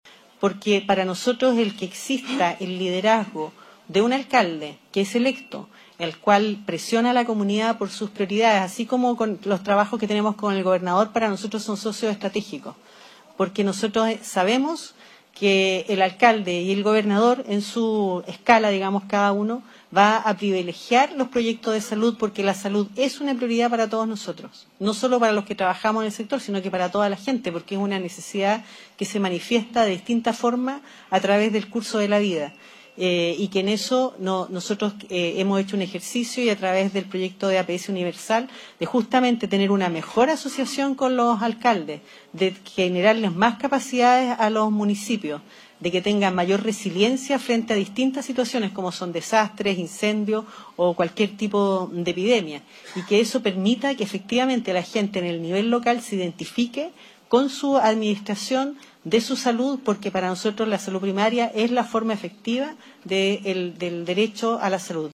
La reunión, desarrollada en la sala de conferencias Deloitte de la Facultad de Ciencias Económicas y Administrativas de la Universidad Austral de Chile, tuvo como objetivo abordar los desafíos y brechas que enfrentan las comunas de la Región de Los Ríos en materia sanitaria, especialmente en lo relacionado con la red de atención primaria y hospitalaria.
CUNA-MINISTRA-DE-SALUD-XIMENA-AGUILAR.mp3